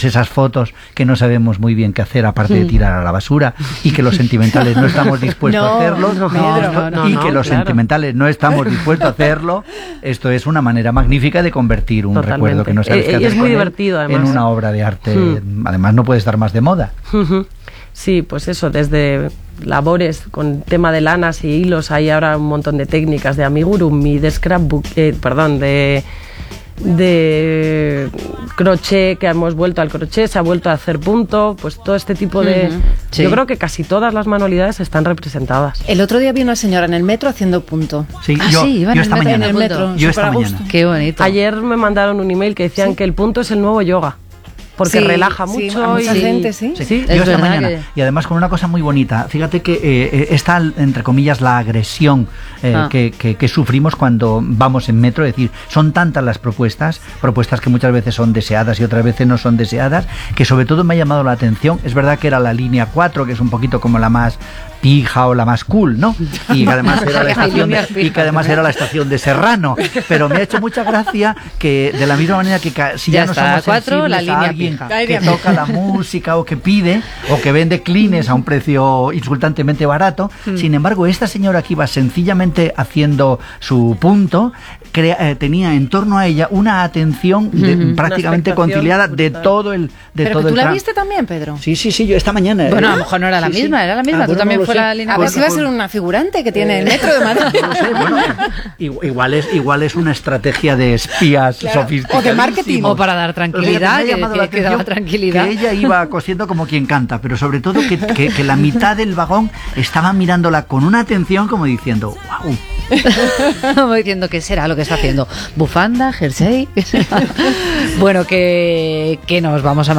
Entrevista Cadena Ser (Noviembre 2013)